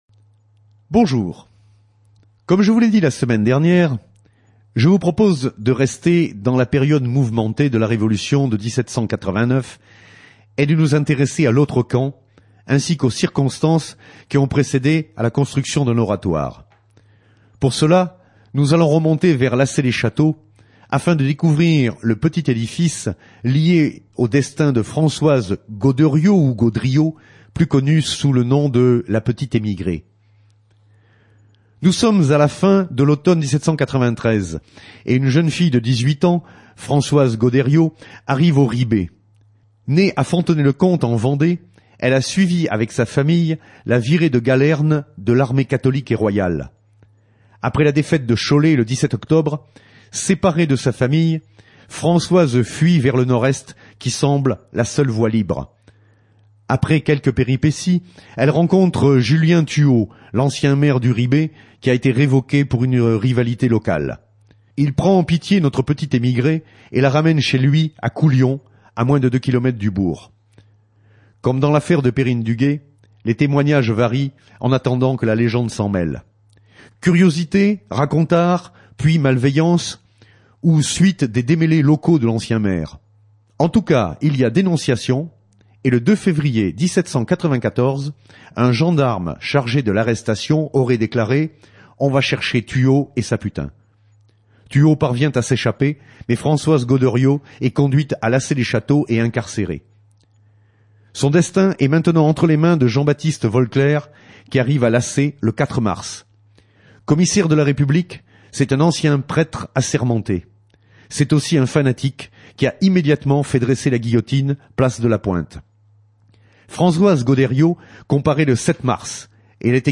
La S.AH.M. sur Radio Fidélité